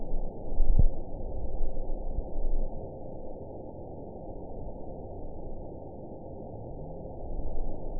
event 920285 date 03/12/24 time 11:00:31 GMT (1 year, 1 month ago) score 8.12 location TSS-AB01 detected by nrw target species NRW annotations +NRW Spectrogram: Frequency (kHz) vs. Time (s) audio not available .wav